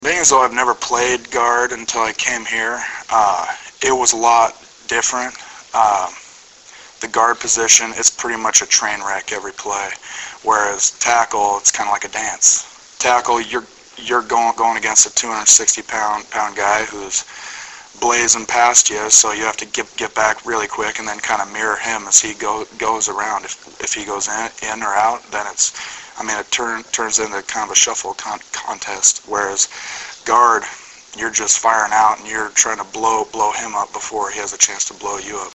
Preseason Press Conference